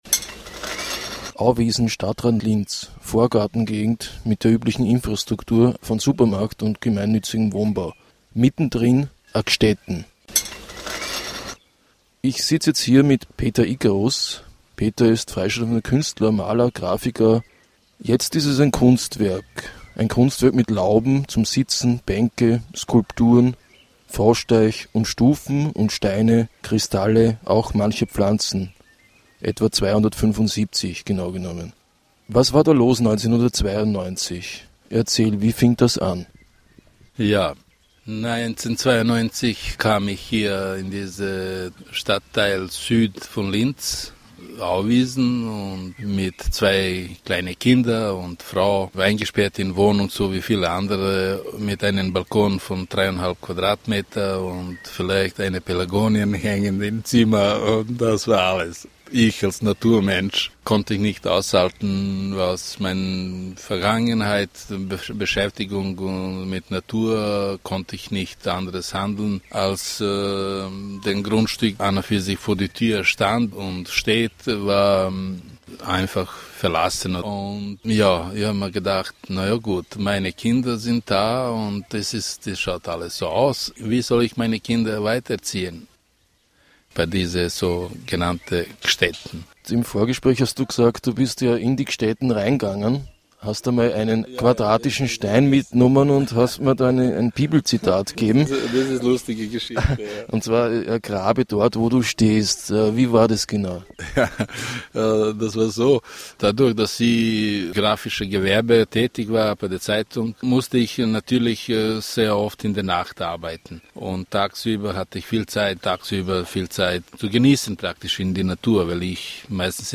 Ein Interview
Format: Stereo 44kHz